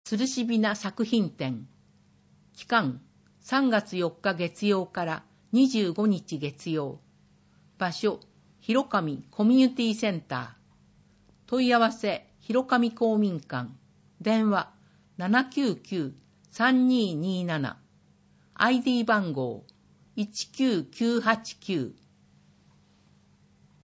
文字を読むことが困難な視覚障害者や高齢者や、市報を聞きたい方のために、「魚沼音声訳の会」のご協力により市報うおぬま音声版（ＭＰ3）をお届けします。